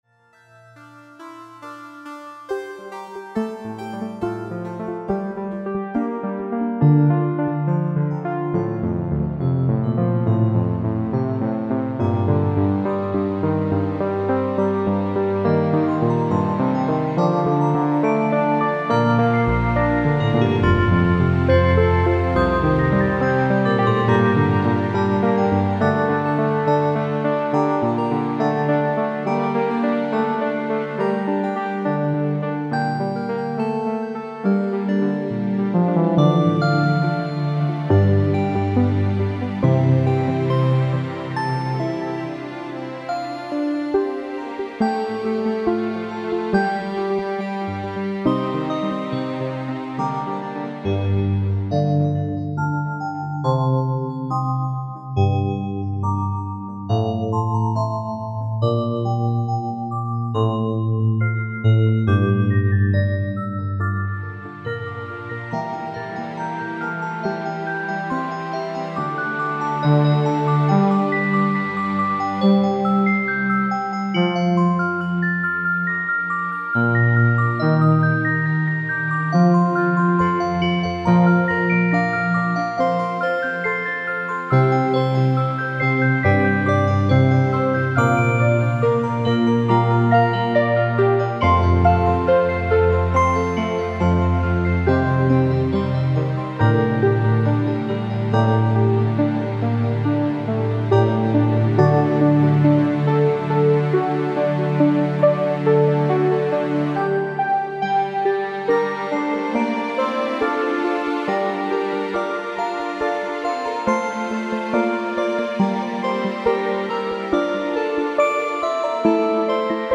A motet from the 16th Century